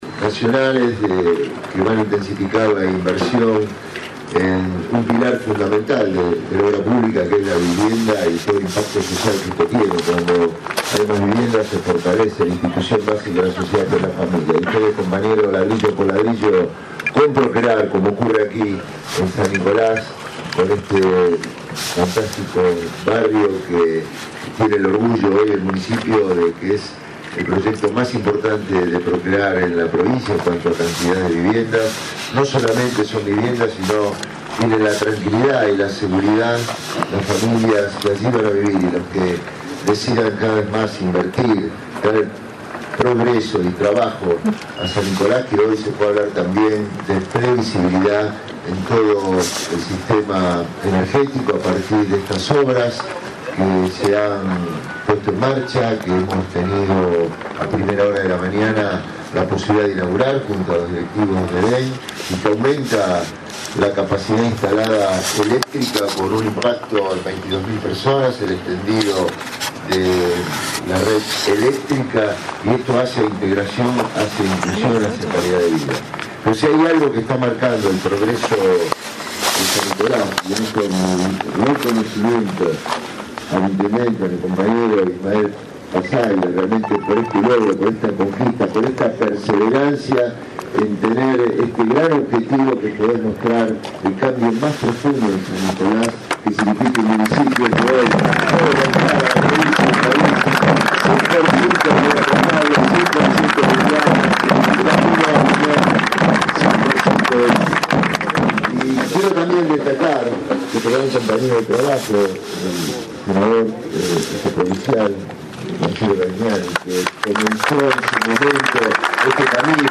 INAUGURACIÓN DE LA PLANTA DE TRATAMIENTO DE EFLUENTES CLOACALES EN ZONA SUR
Gobernador de la Prov. de Bs. As. Daniel Scioli